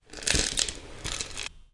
描述：这个样本包包含了两种不同的雨杖以常规方式演奏的样本，以及一些简短的附带样本。
这两根雨杖是通过将一个约瑟夫森C42话筒绑在乐器主体的两端来记录的。 同时，一个Josephson C617全向话筒被放置在大约一英尺远的地方，以填补中间的图像，其目的是创造一个非常宽和接近的立体声图像，仍然是完全单声道兼容的。
注意：在一些比较安静的样本中，增益被提高了，背景中可以听到一个有问题的日光灯。
标签： 智利 智利 加纳 加纳 乐器 打击乐器 秘鲁 秘鲁 雨声器 拨浪鼓 振动筛 风暴 天气
声道立体声